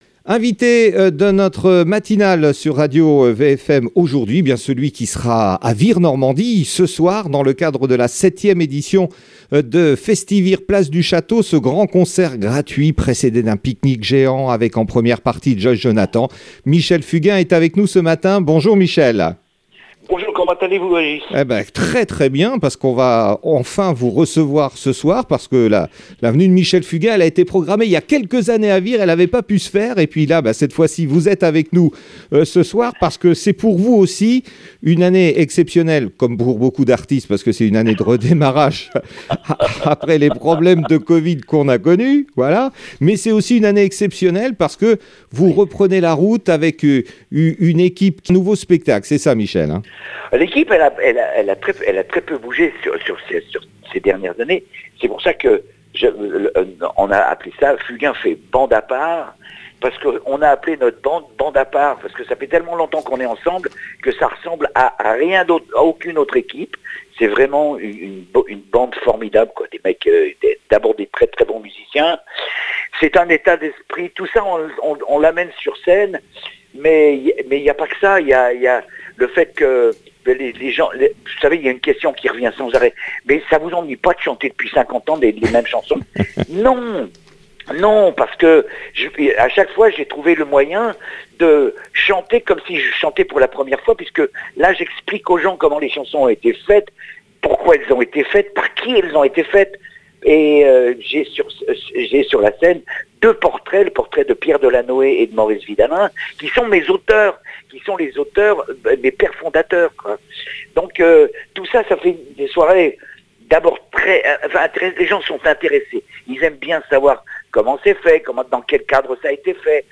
Interview de Michel Fugain